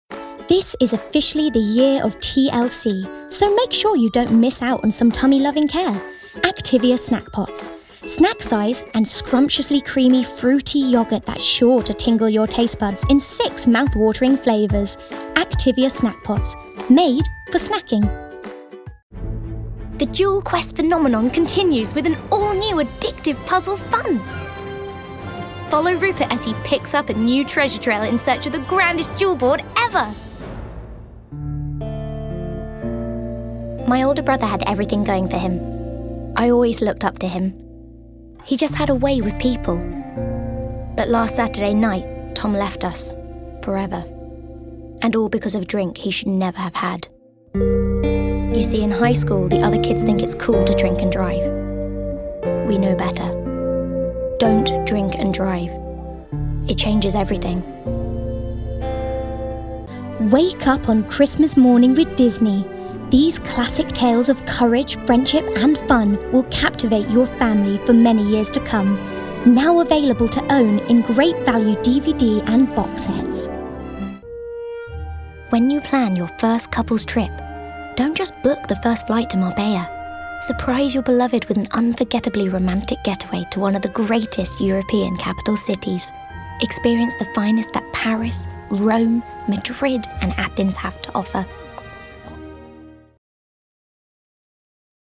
Commercial
kids-30s - lively, fun, energy
Standard English/RP, London/Cockney, American, Yorkshire, Irish
Actors/Actresses, Corporate/Informative, Modern/Youthful/Contemporary, Natural/Fresh, Smooth/Soft-Sell, Quirky/Interesting/Unique, Character/Animation, Upbeat/Energy